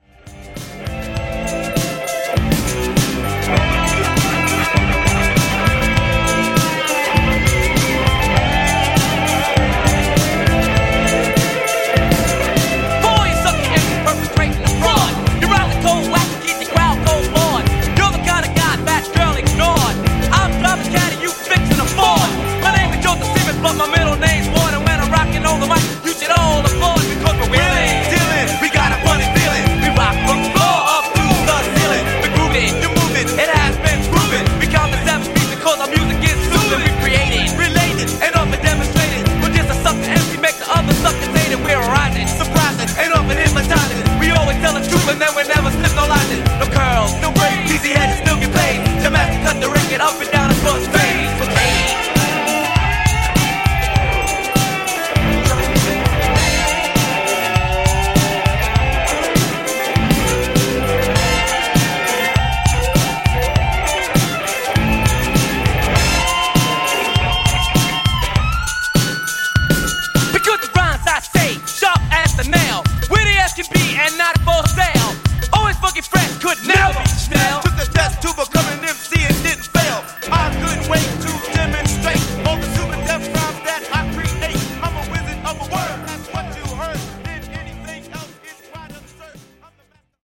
80s Rap Extended)Date Added